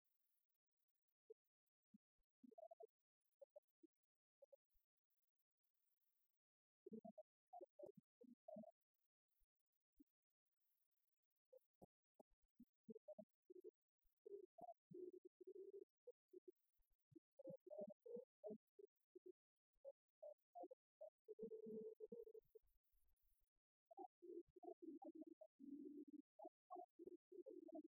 Informateur(s) Club des retraités de Beaupréau association
Chansons du Club des retraités
Pièce musicale inédite